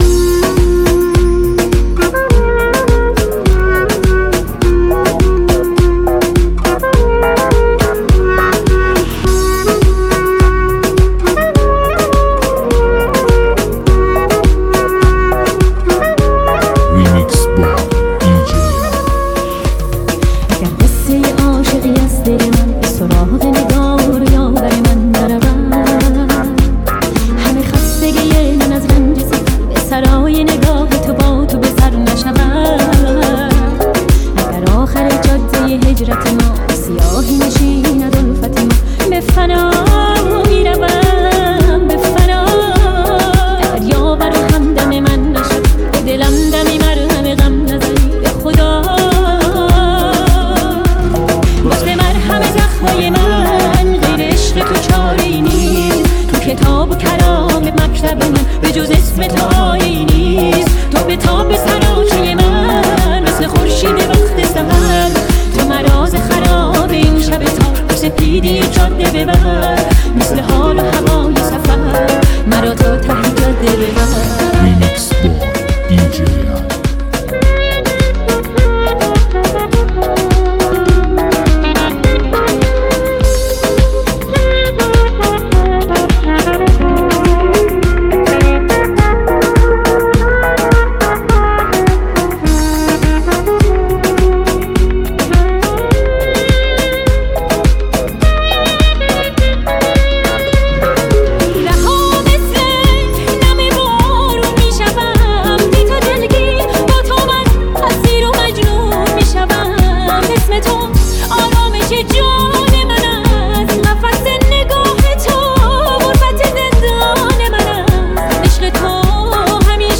موسیقی متفاوت و پرانرژی برای لحظاتی پر از احساس و شادی.